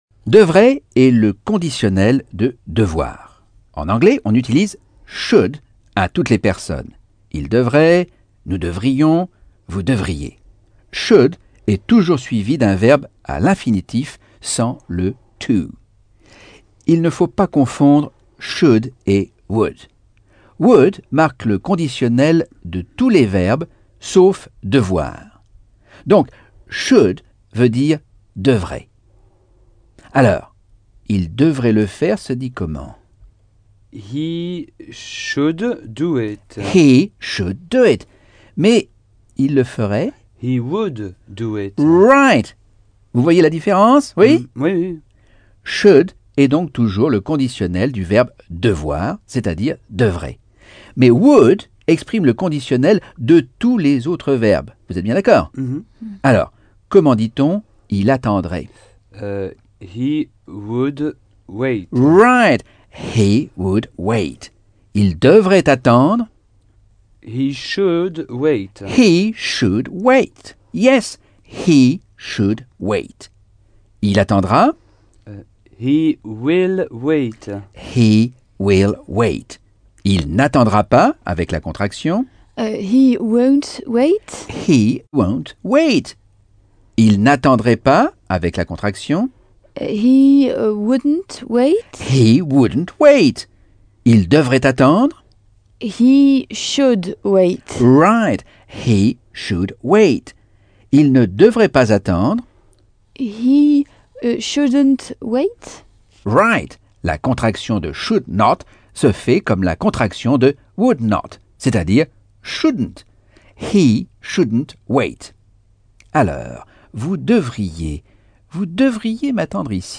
Leçon 9 - Cours audio Anglais par Michel Thomas - Chapitre 6